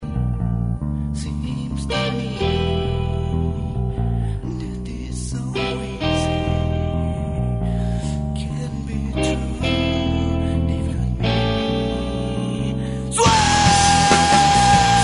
kytary, zpěv
baskytara
bicí